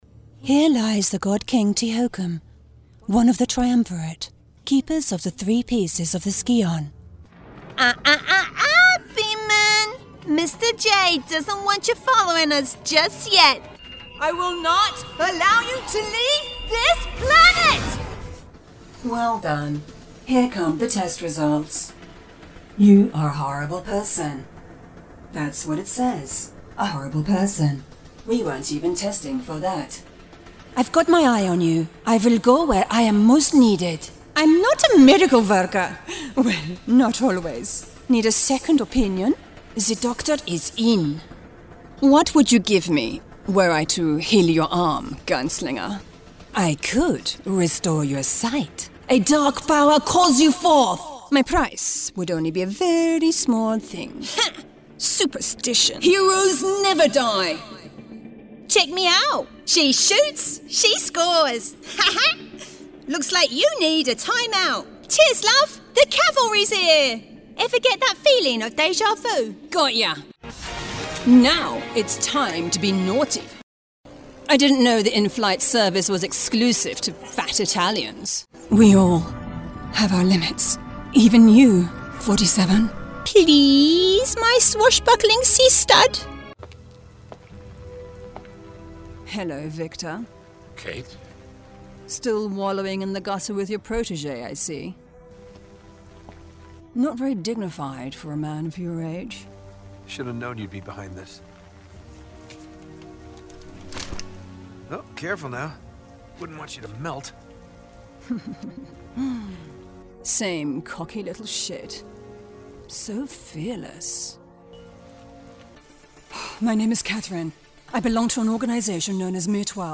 British Female Voice Over Video Game Demo
Voz Caricata 02:35
- Broadcast quality recording from her professional home studio